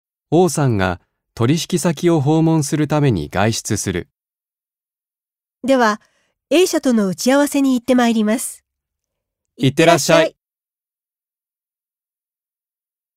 1.1. 会話（社内での日常の挨拶）